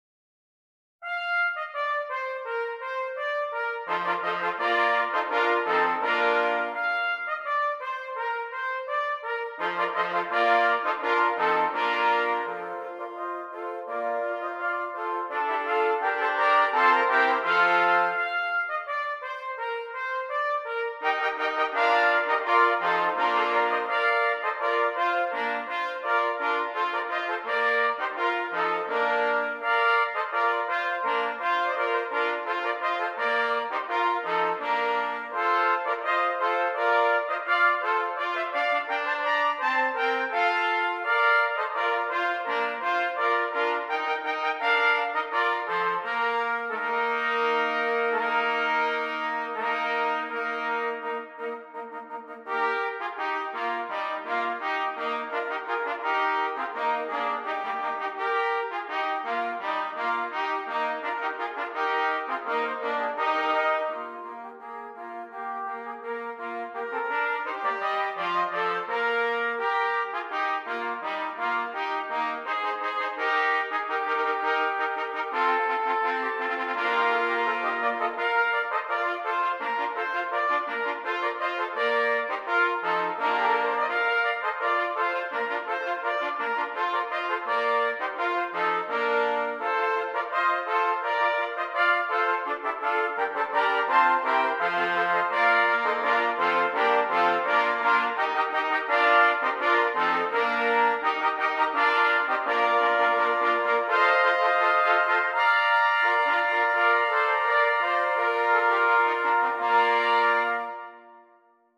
6 Trumpets
Traditional Carol